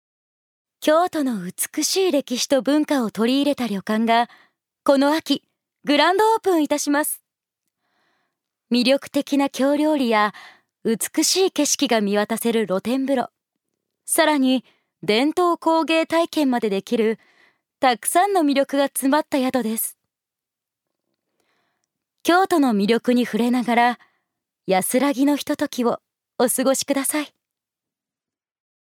女性タレント
音声サンプル
ナレーション１